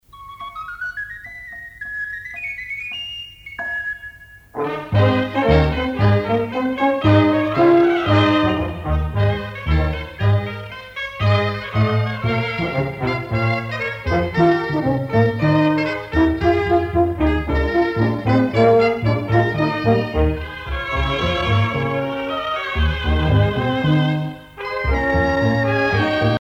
Catalogne
danse : sardane